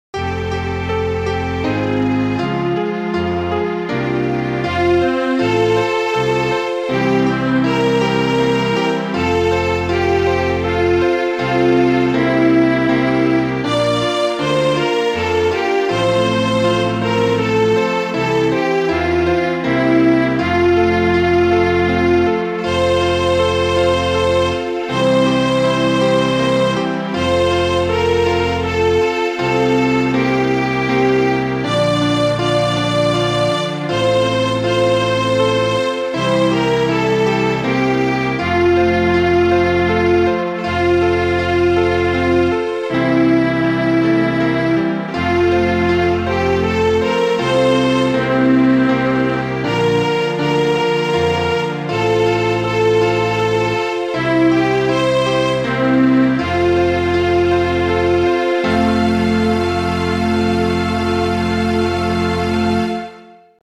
BIAB takes on Mozart with predictable results.